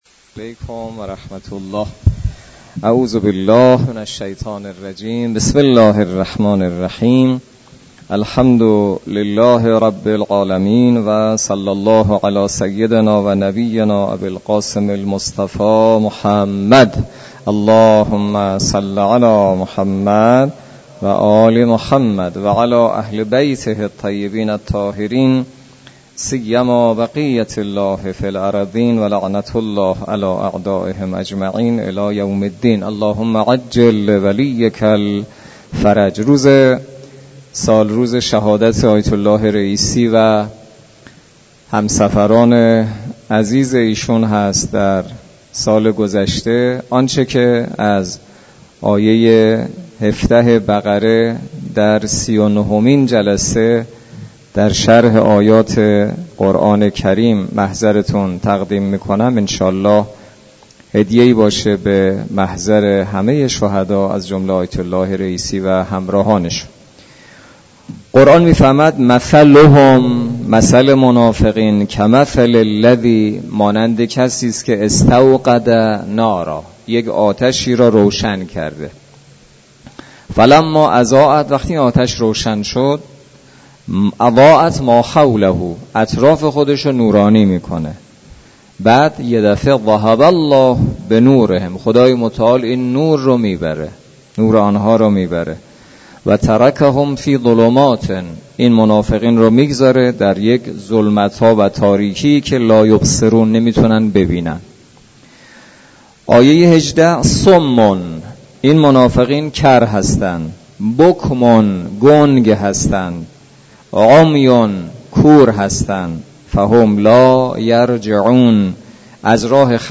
برگزاری بیست و دومین جلسه تفسیر سوره مبارکه بقره توسط امام جمعه کاشان در مسجد دانشگاه.